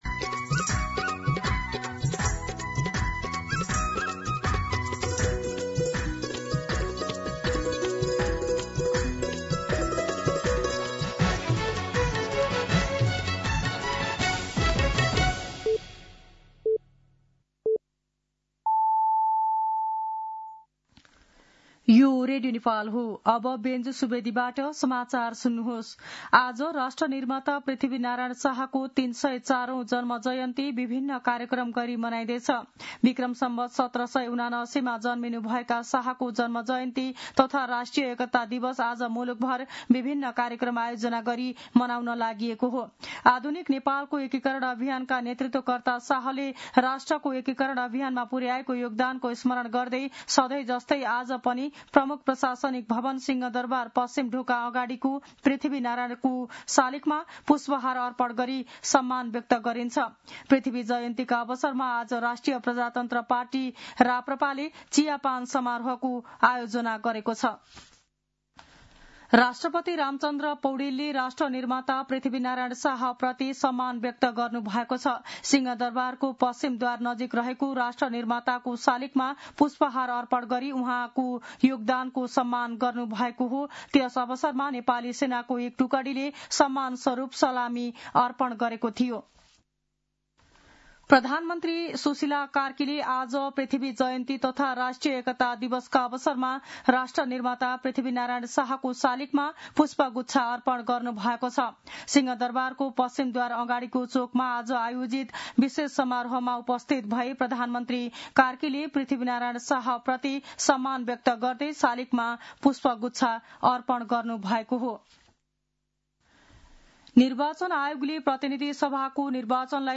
दिउँसो १ बजेको नेपाली समाचार : २७ पुष , २०८२
1pm-News.mp3